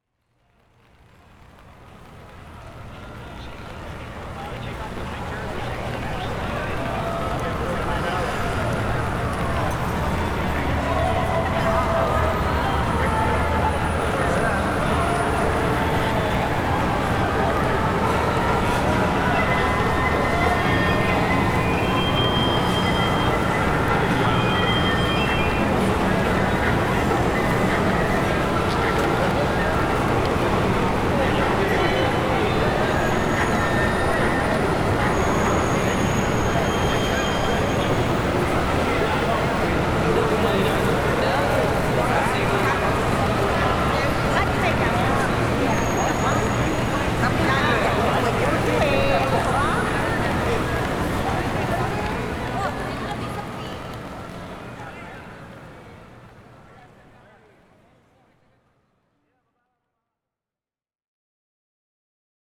On entend a présent des voix de langues différentes, de la musique, le tout flottant dans le coton grâce à la réverbération.
C’est une méthode efficace que j’utilise très souvent et ici j’ai choisi d’ajouter de la pluie sur un parapluie.
06-pluie.wav